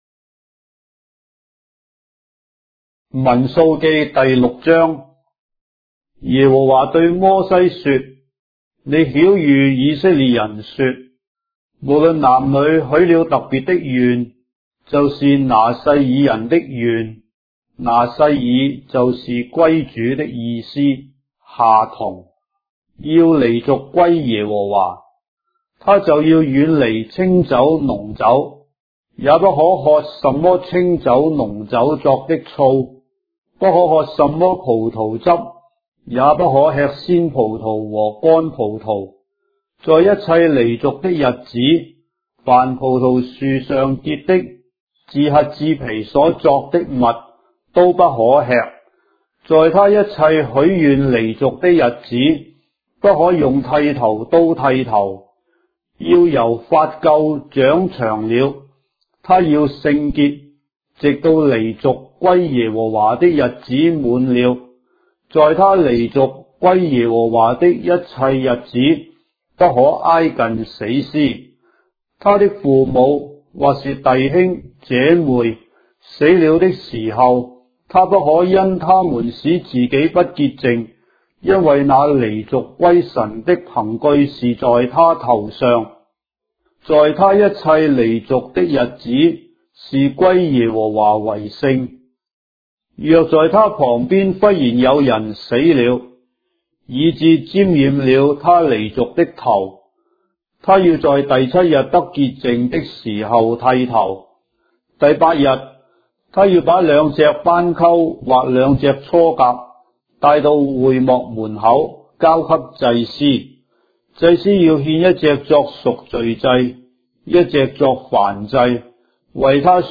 章的聖經在中國的語言，音頻旁白- Numbers, chapter 6 of the Holy Bible in Traditional Chinese